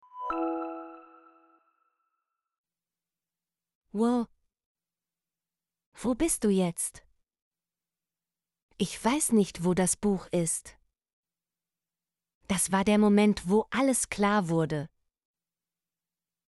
wo - Example Sentences & Pronunciation, German Frequency List